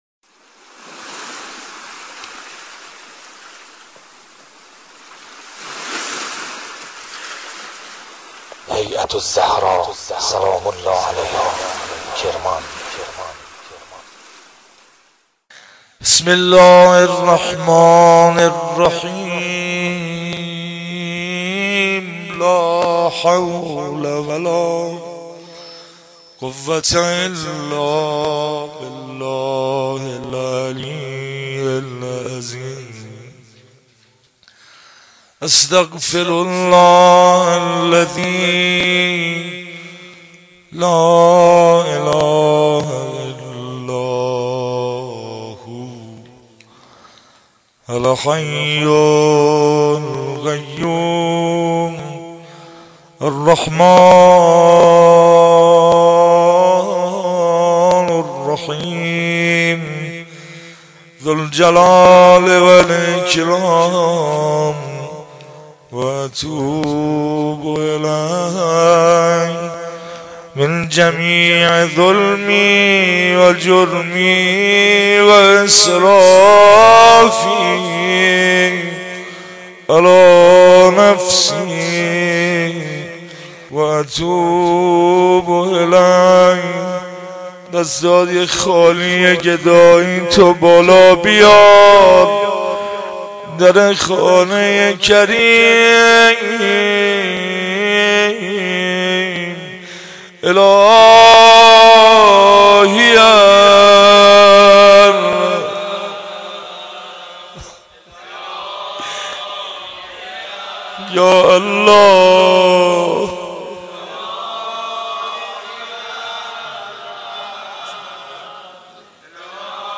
مناجات
جلسه هفتگی96/5/11